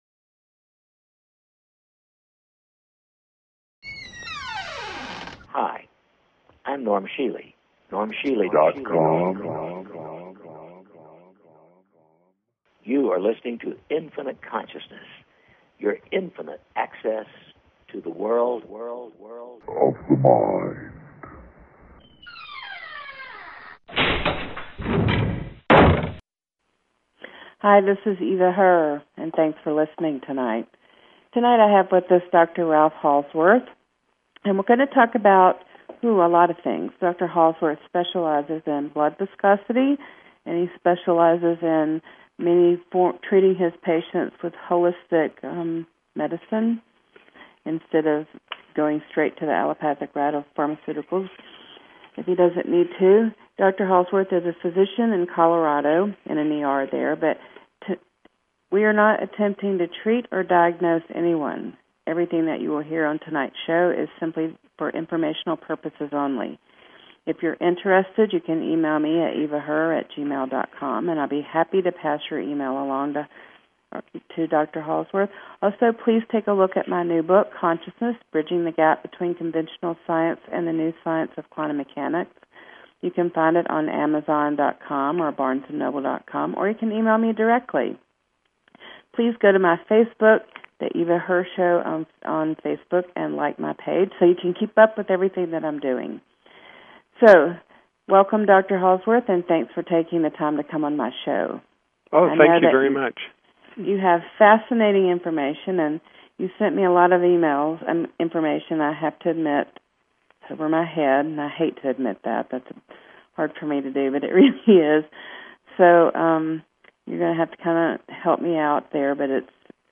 Courtesy of BBS Radio